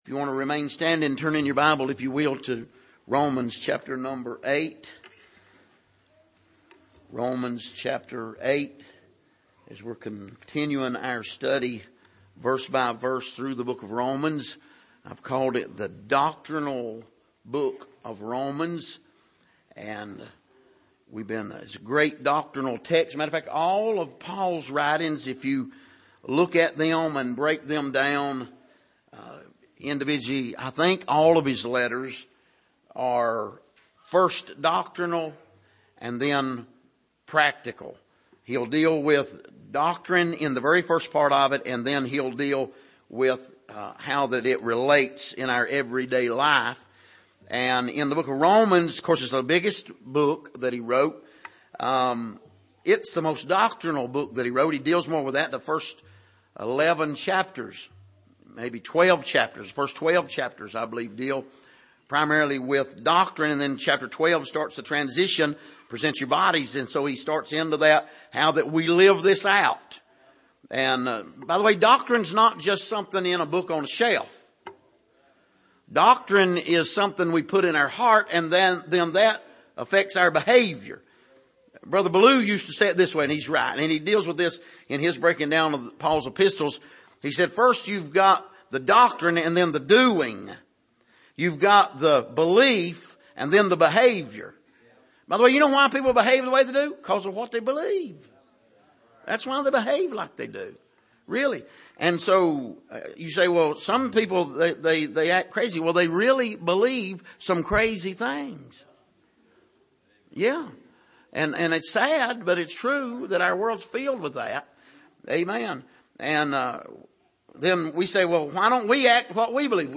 Passage: Romans 8:26-27 Service: Sunday Morning